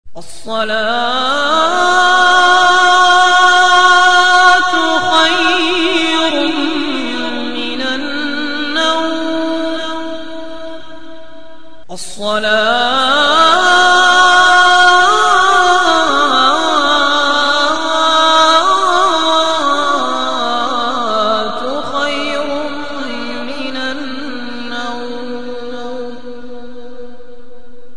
Islamic Alarm Tone Designed To Wake You Up For Prayer.